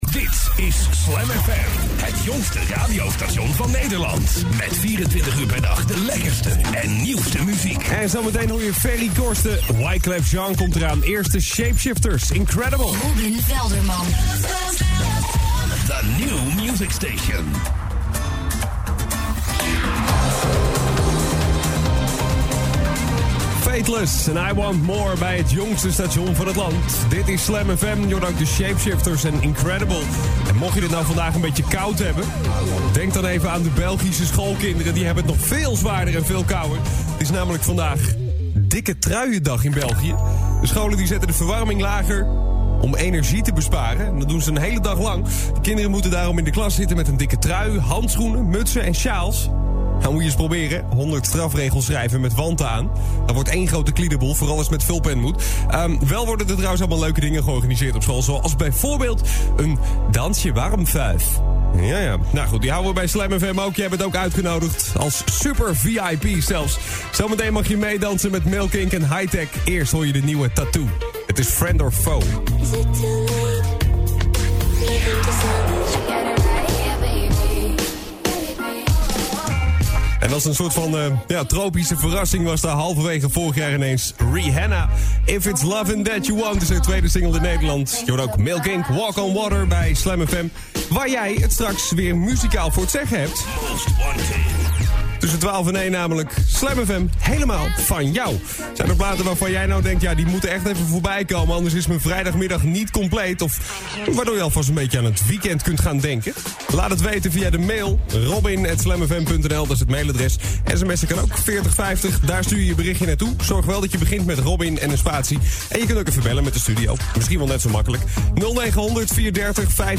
Voice-over opname